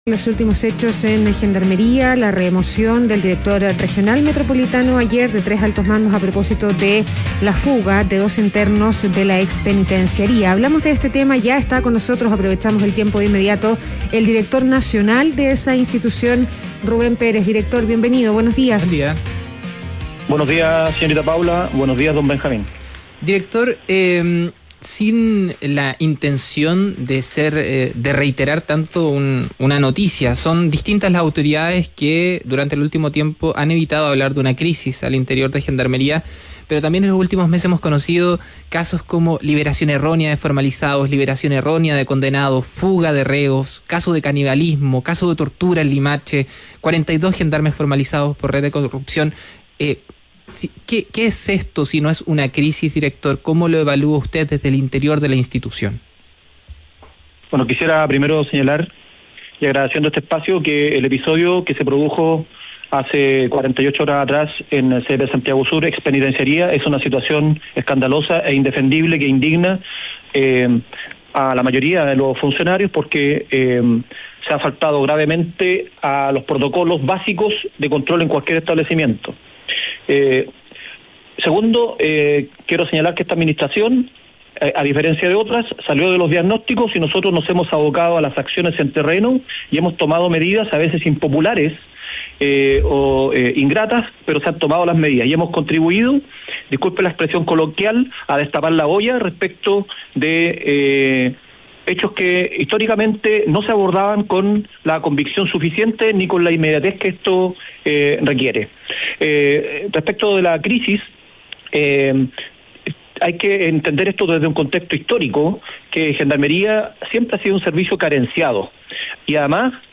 Entrevista al director nacional de Gendarmería, Rubén Pérez - ADN Hoy